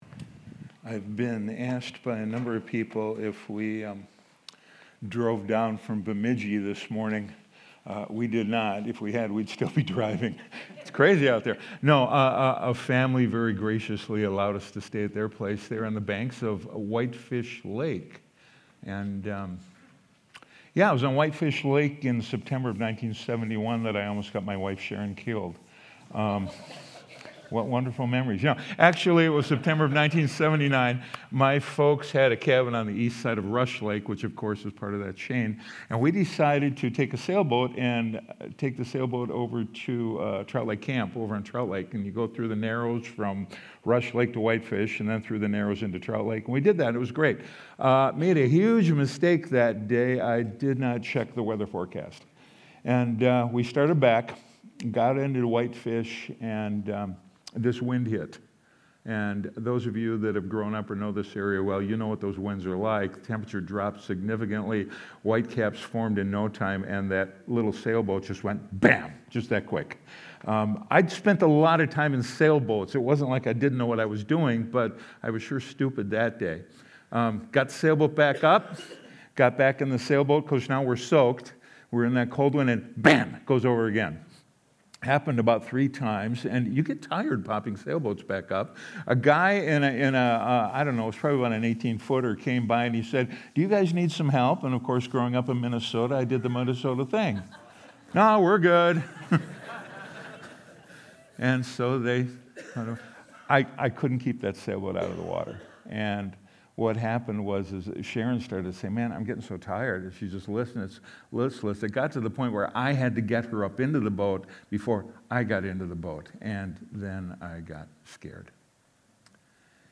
Sunday Sermon: 12-28-25